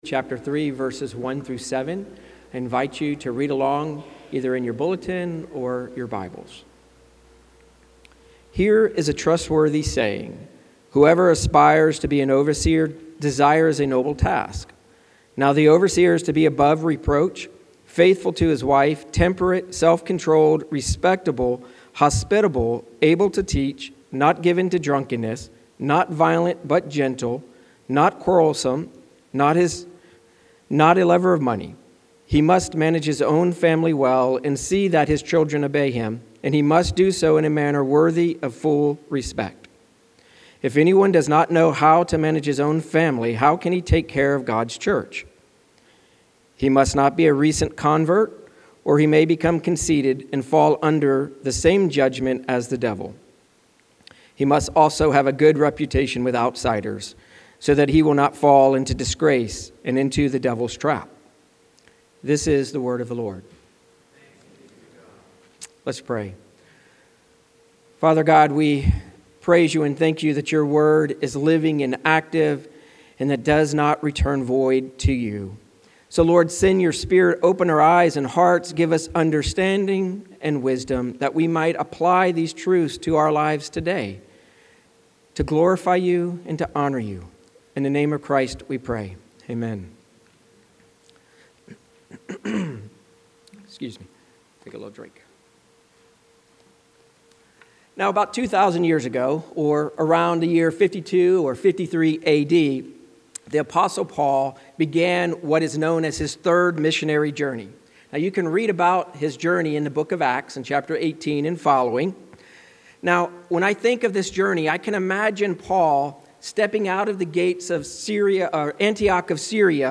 Sermons by the Pastors of Astoria Community Church